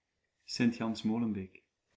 Molenbeek-Saint-Jean (French, pronounced [molœnbek sɛ̃ ʒɑ̃] ) or Sint-Jans-Molenbeek (Dutch, pronounced [sɪɲˈtɕɑns ˈmoːlə(m)ˌbeːk]
Nl-Sint-Jans-Molenbeek.ogg.mp3